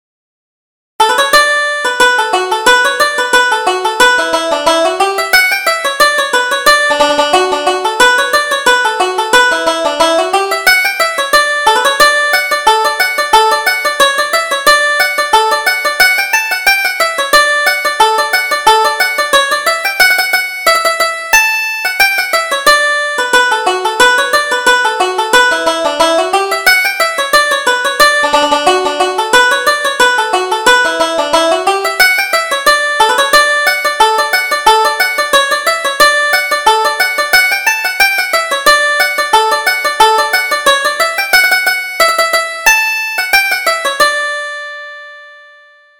Reel: The Christening